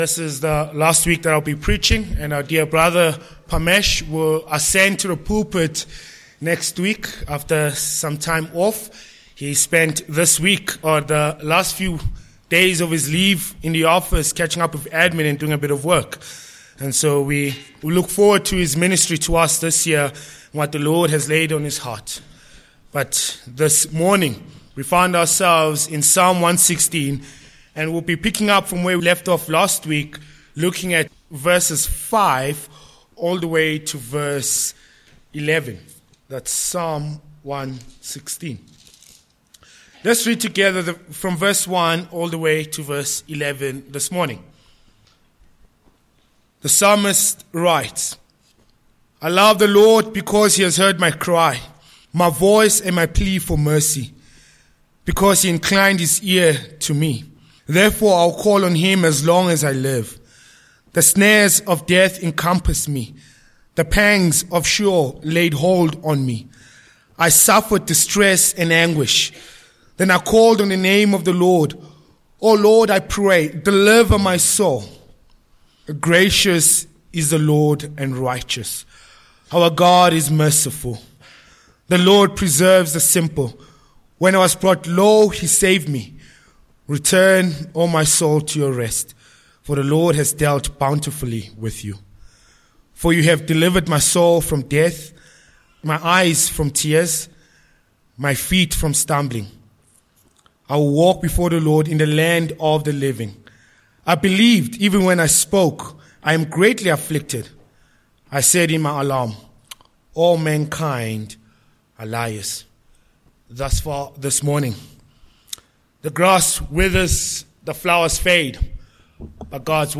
Sermon points: 1. The Lord Deals bountifully with His Own v5-7